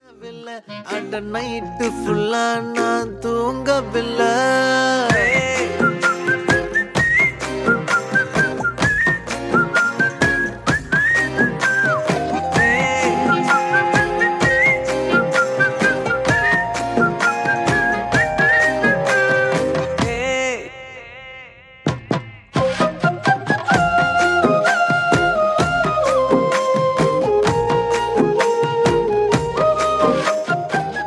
love song ringtone
melody ringtone romantic ringtone